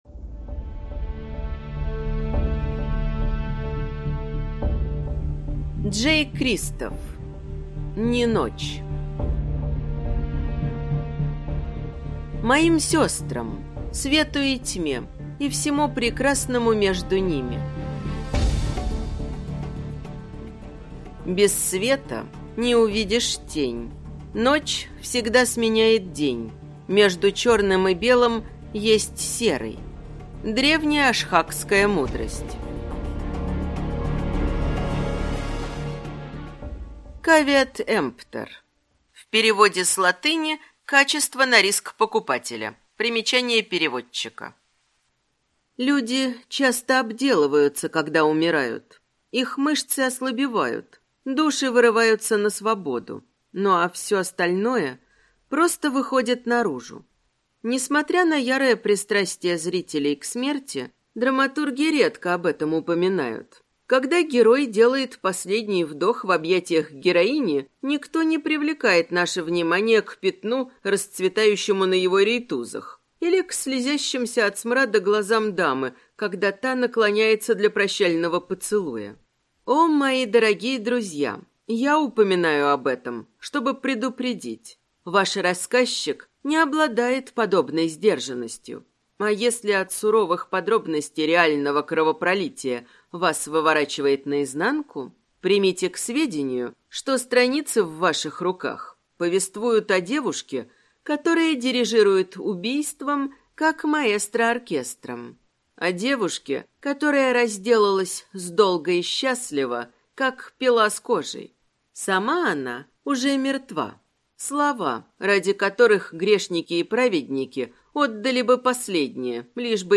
Аудиокнига Неночь | Библиотека аудиокниг